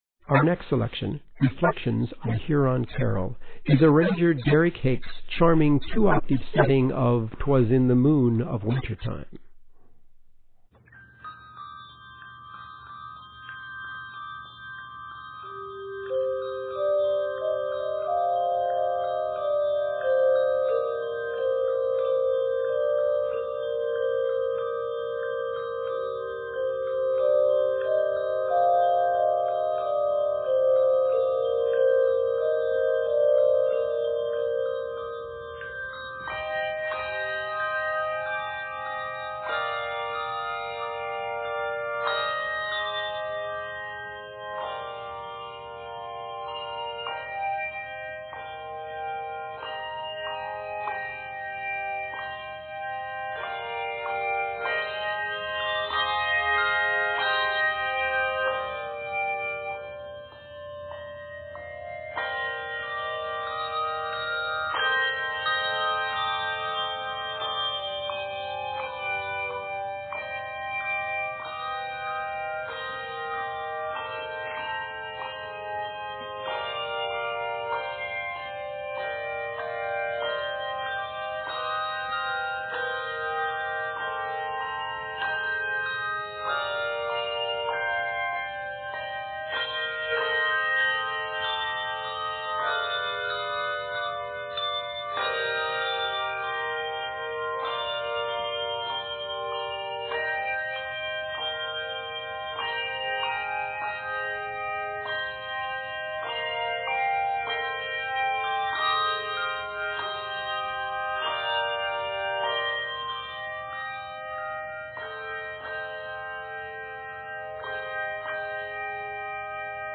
" starts and ends with battery bells on melody.
2 octave setting